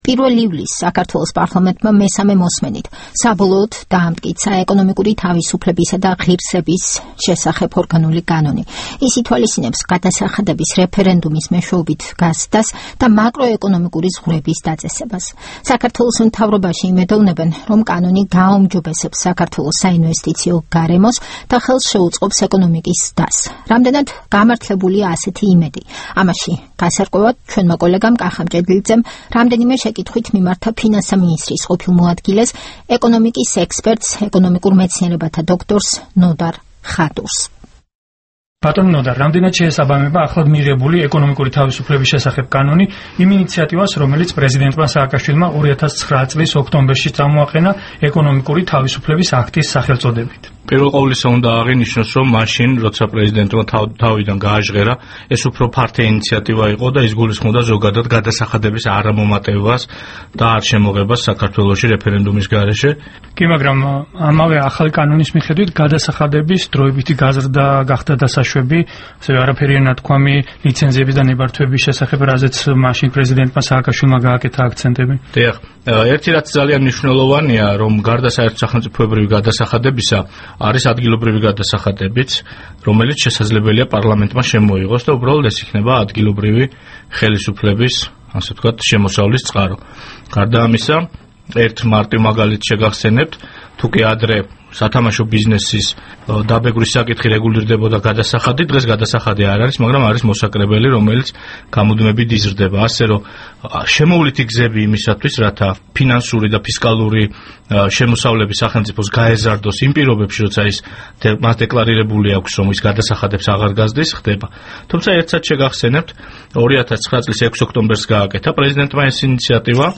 რადიო თავისუფლებამ ინტერვიუ სთხოვა ეკონომიკურ მეცნიერებათა დოქტორ ნოდარ ხადურს.
საუბარი ნოდარ ხადურთან